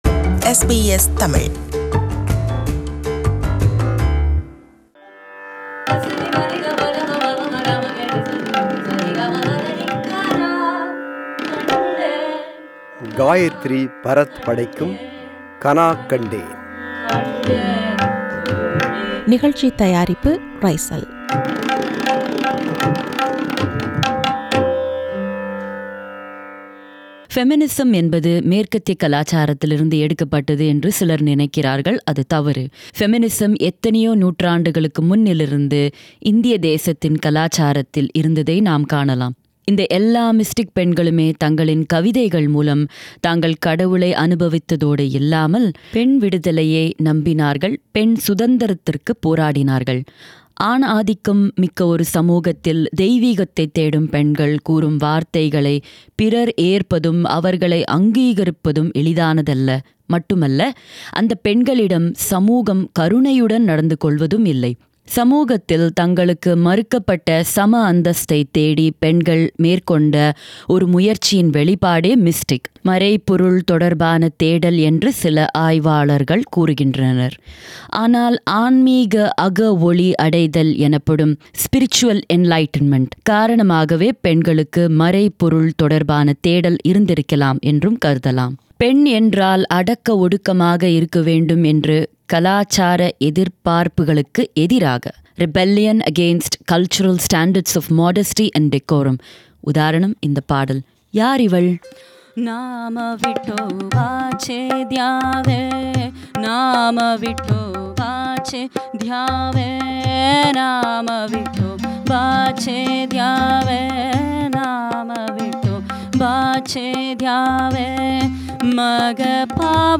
தெய்வீகத்தை, மறைபொருளைத் தேடிய ஒன்பது பெண்களின் வாழ்க்கையையும், அவர்களின் பாடல்களையும் (mystic women) பாடி, விவரிக்கும் தொடர் இது.
Harmonium
Tabla
Mridangam
Tanpura
Studio: SBS